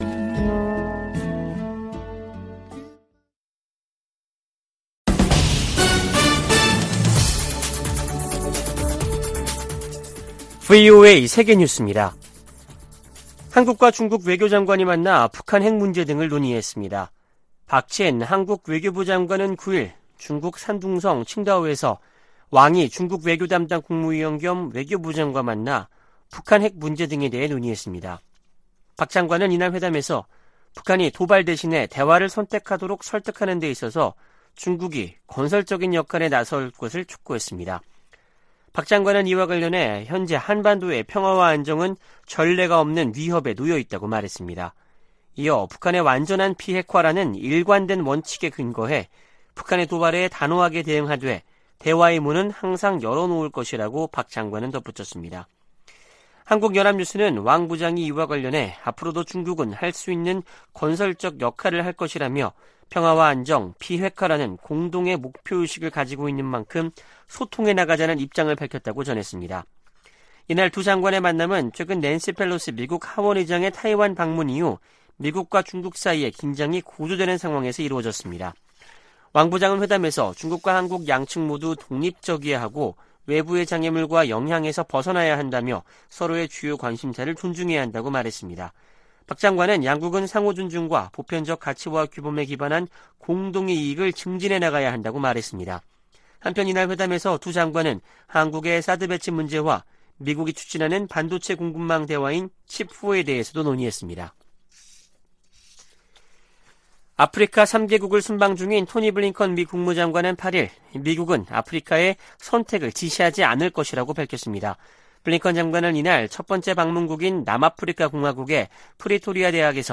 VOA 한국어 아침 뉴스 프로그램 '워싱턴 뉴스 광장' 2022년 8월 10일 방송입니다. 미국 정부가 북한 해킹조직 라자루스가 탈취한 가상화폐의 세탁을 도운 믹서 업체를 제재했습니다. 국무부는 우크라이나 친러시아 세력의 독립인정을 강력히 규탄하며 북한 노동자 파견은 대북 제재 위반이라는 점을 분명히 했습니다. 미 하원의원들이 베트남전쟁에 미군과 함께 참전했던 미국 내 한인들에게 의료 혜택을 제공하는 입법을 촉구했습니다.